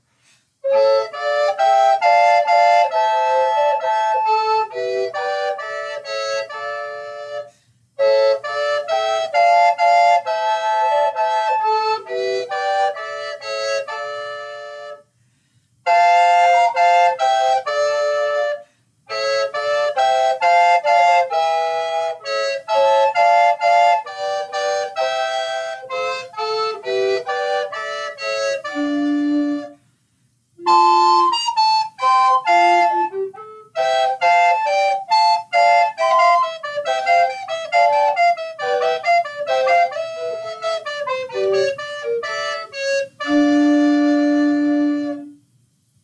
recorder trio. longtime fans will remember this one from when I multitracked myself singing it